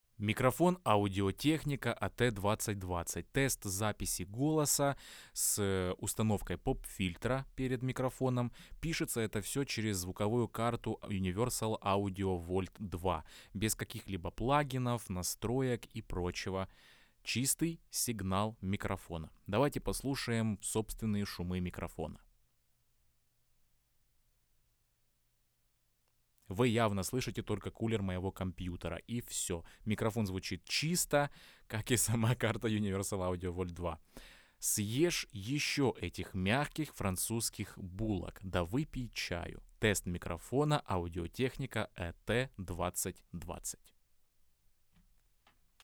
Обзор и ТЕСТ звука Audio-Technica AT2020, сравнение с Lewitt 240 PRO - Плюсы и минусы бюджетного микрофона за 100$.
Микрофон звучит нейтрально и мягко. Голос записывается натурально.
Собственный шум микрофона на низком уровне, его почти не слышно.
Записи без обработки, на одном уровне Gain.
audio-technica-at2020-ru.mp3